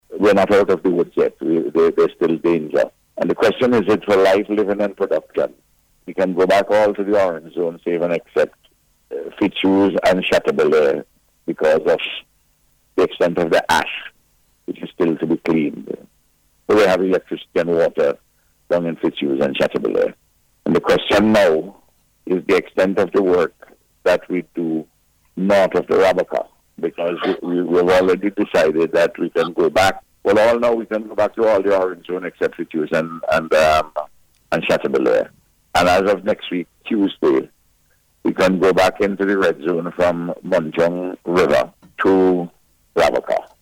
Speaking on NBC Radio’s Eyeing La Soufriere program this morning, the Prime Minister said persons are also being allowed to return to the Orange Zone, except Fitz Hughes and Chateaubelair.